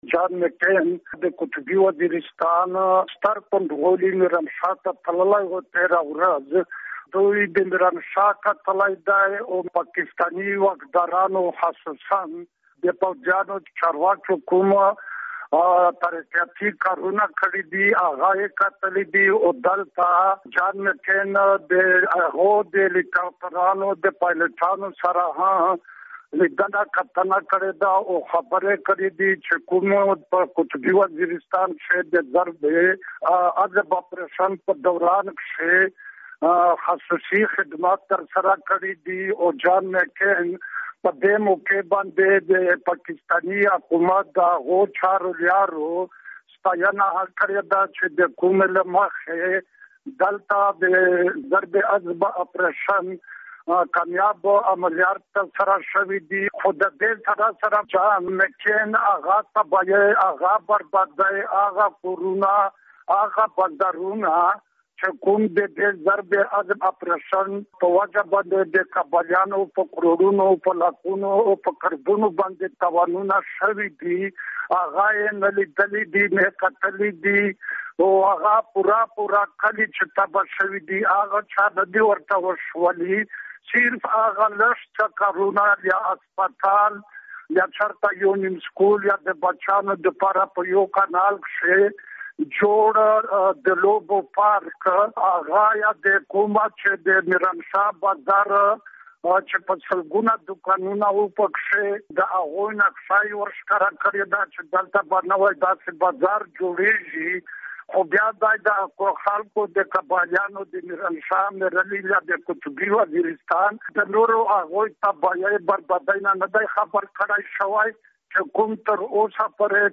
د مشال راډيو له خبریال سره د جان مککین د سفر په اړه مرکه